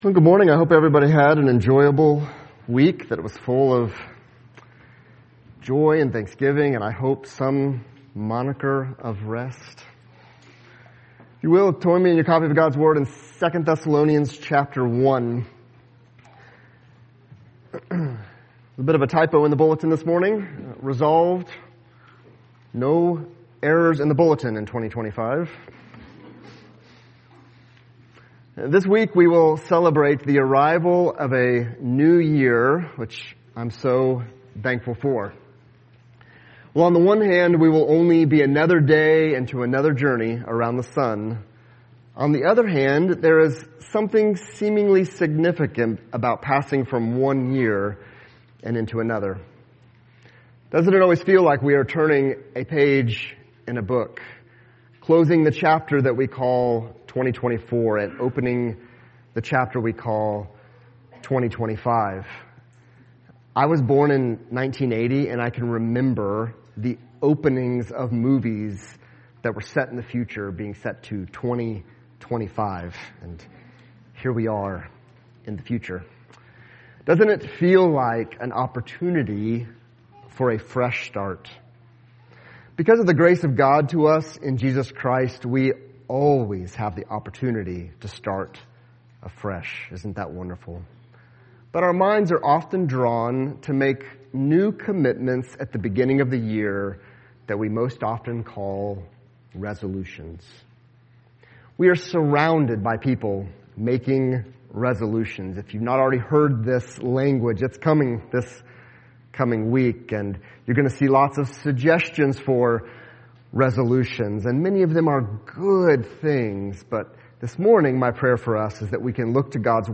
Sermons – Christ Family Church podcast